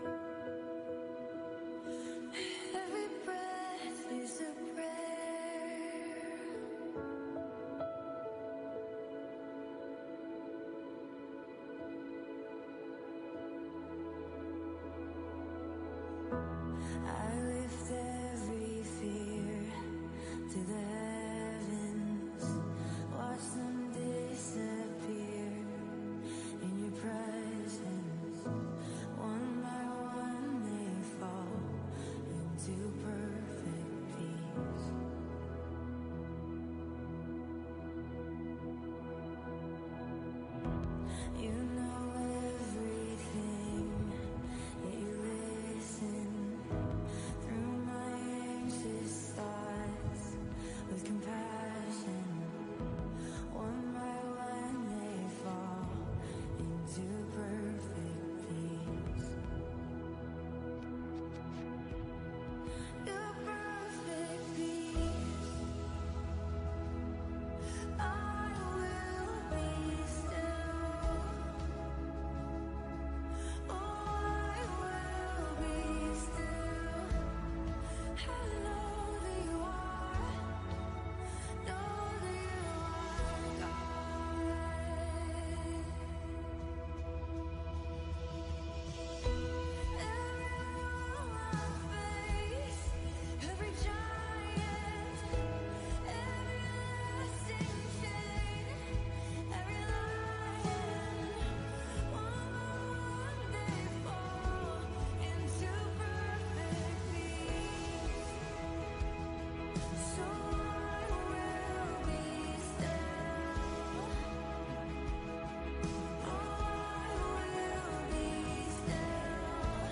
GoDie -teil 2 " wir sind ..." ~ VCC JesusZentrum Gottesdienste (audio) Podcast